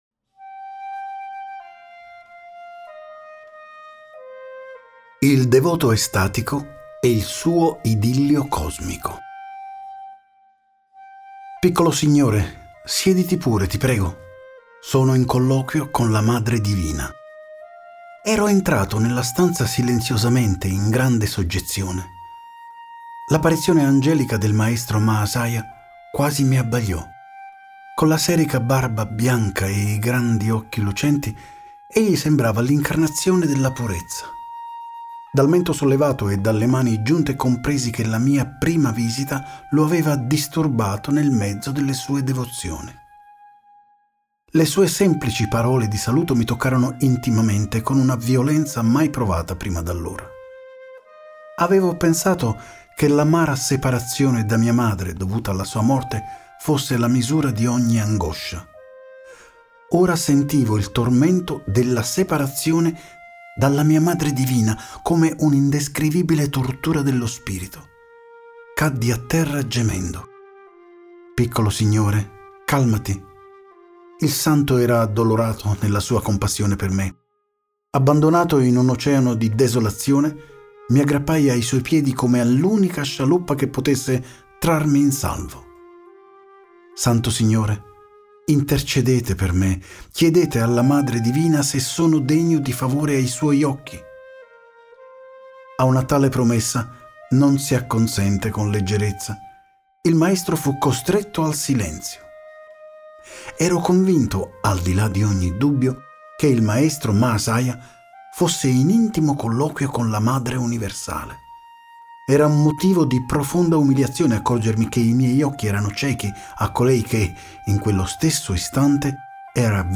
Versione tascabile con Audiolibro scaricabile letto da Enzo Decaro
estratto-autobiografia-di-uno-yogi-audiolibro-capitolo-9.mp3